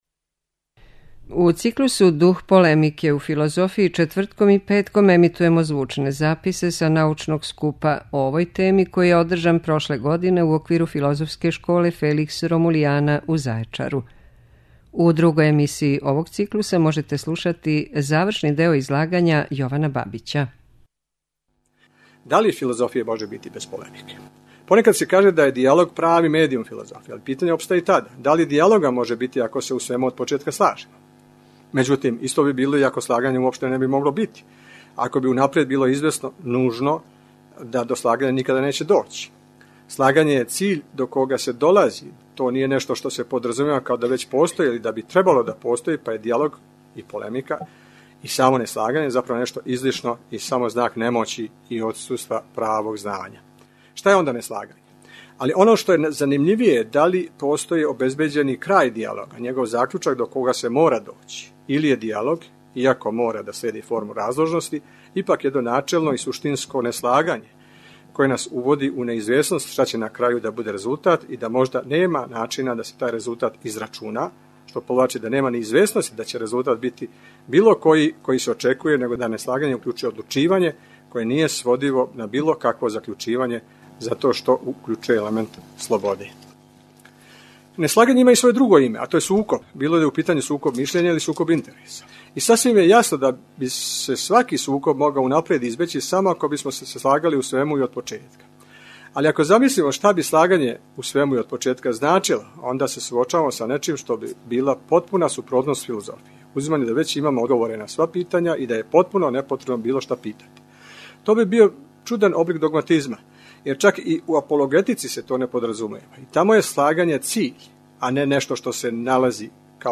У циклусу ДУХ ПОЛЕМИКЕ У ФИЛОЗОФИЈИ четвртком и петком емитујемо снимке са научног скупа о овој теми, који је одржан прошле године у оквиру Филозофске школе Феликс Ромулиана у Зајечару.
Научни скупови